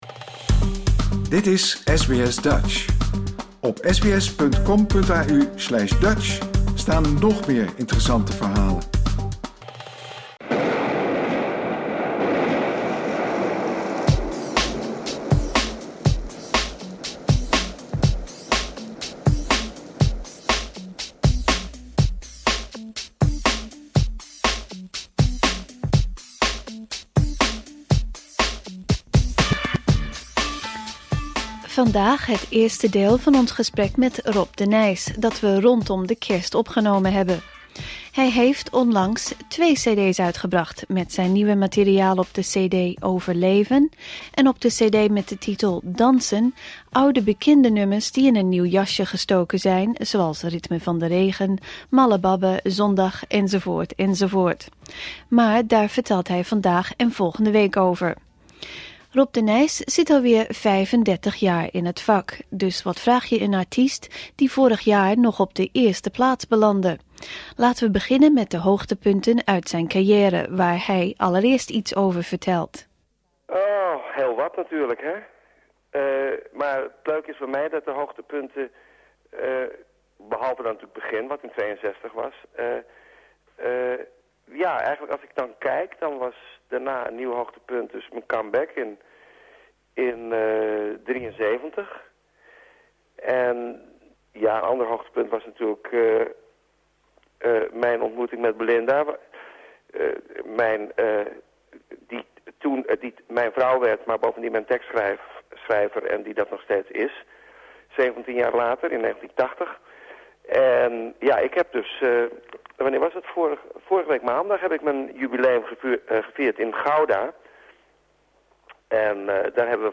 SBS Dutch interview Rob de Nijs deel 1